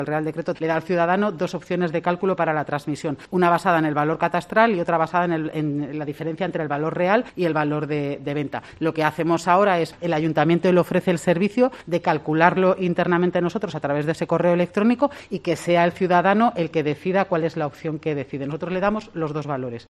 Esmeralda Campos, concejala de Economía de Logroño